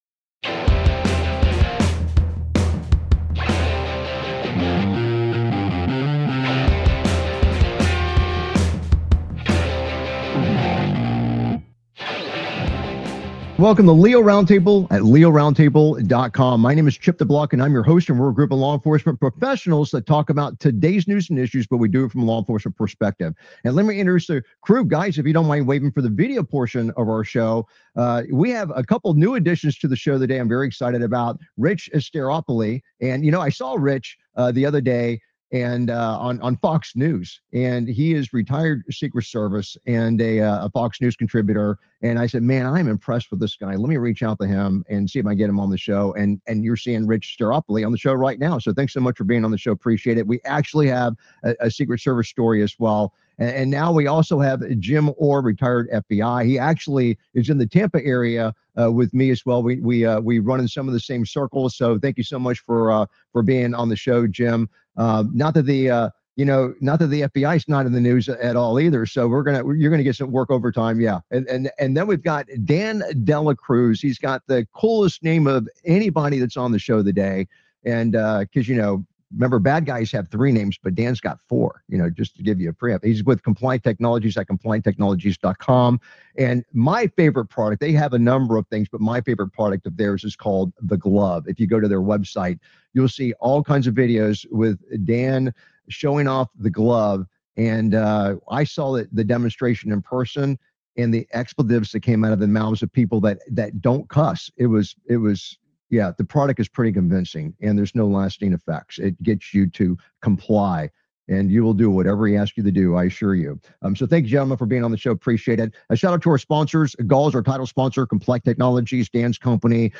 Talk Show Episode, Audio Podcast, LEO Round Table and S10E108, Officer Clings To Windshield And Starts Shooting Bad Guy On Video on , show guests , about True Crime,Tom Homan,AOC,fatal shooting,pursuit,Officer Shooting,Firearms, categorized as Entertainment,Military,News,Politics & Government,National,World,Society and Culture,Technology,Theory & Conspiracy